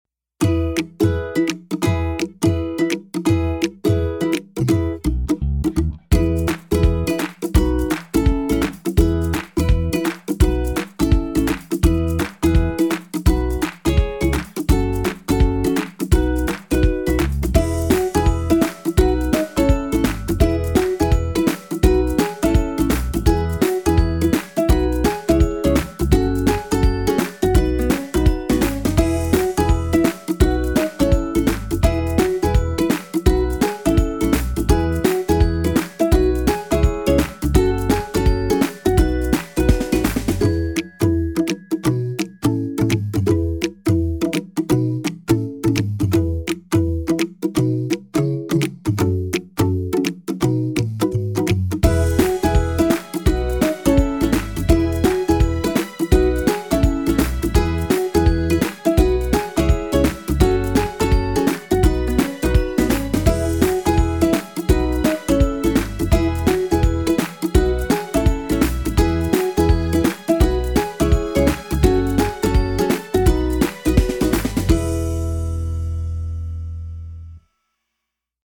Genres: Cheerful-Joyful-Playful